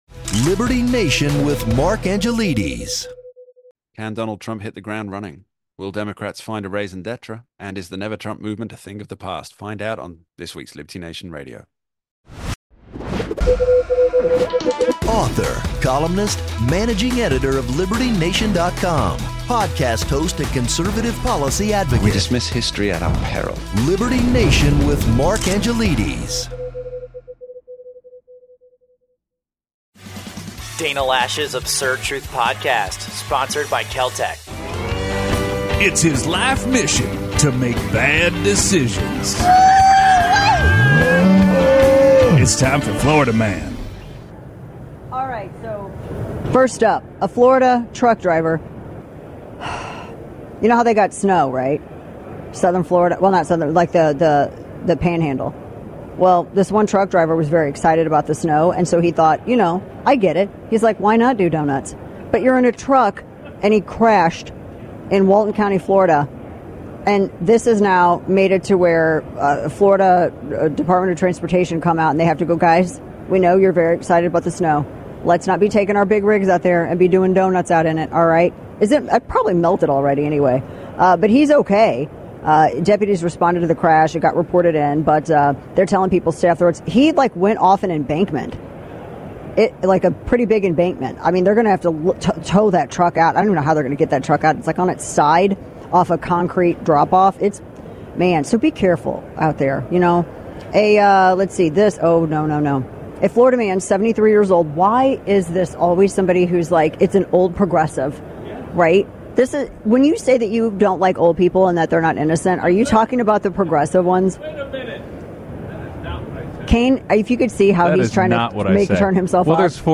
UFC Hall of Famer Ken Shamrock joins us live to chat about the new Administration, men in women's sports, the state of MMA and more.